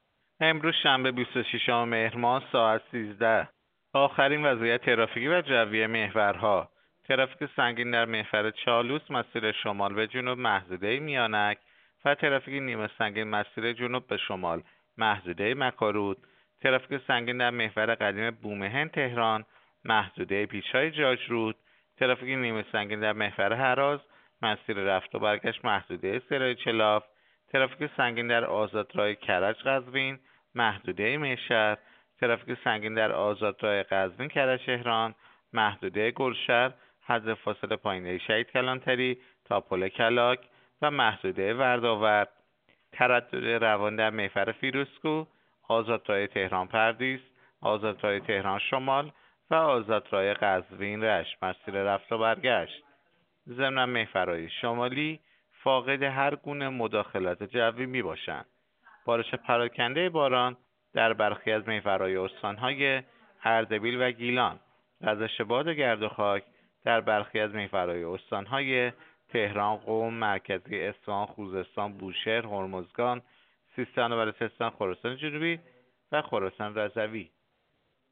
گزارش رادیو اینترنتی از آخرین وضعیت ترافیکی جاده‌ها ساعت ۱۳ بیست‌وششم مهر؛